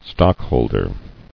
[stock·hold·er]